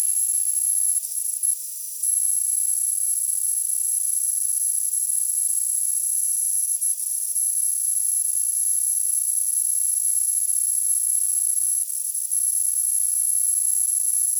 Причем этот писк слышен как снаружи, так и в самих наушниках, только там больше именно писка, а не свиста. Вот, записал как это звучит снаружи.